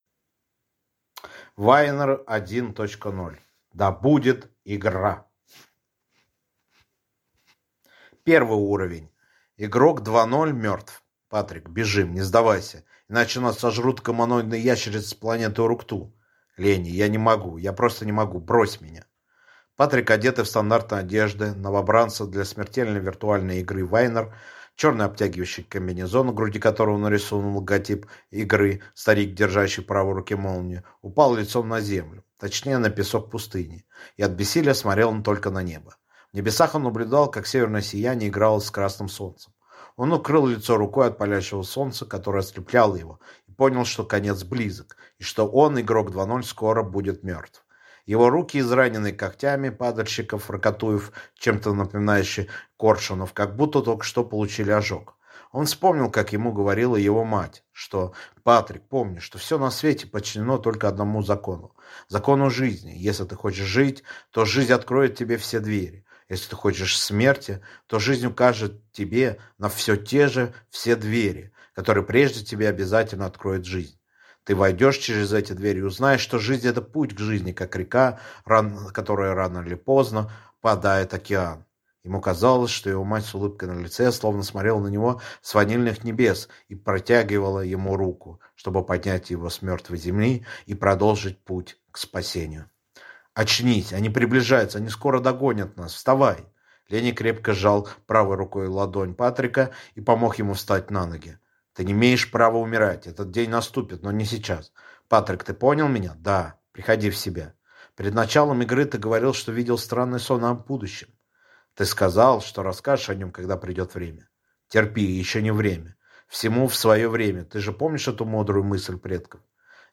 Аудиокнига Вайнар 1.0: Да будет игра!